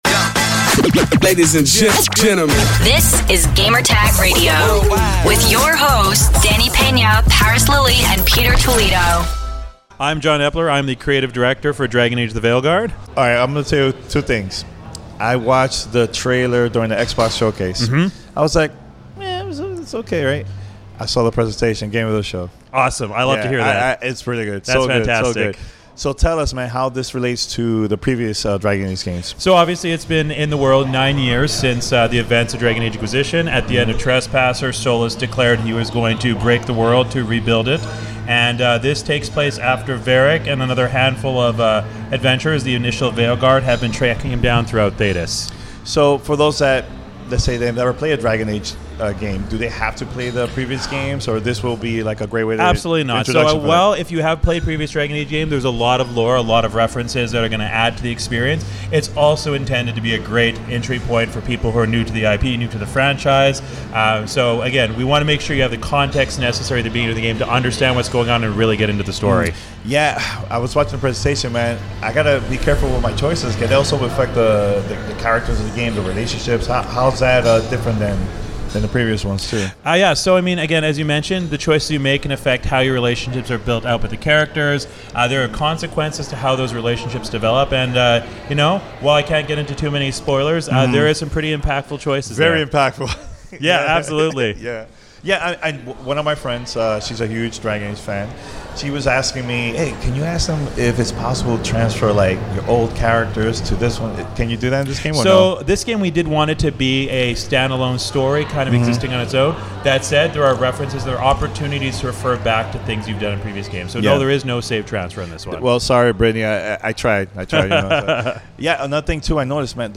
Dragon Age: Veilguard - A Conversation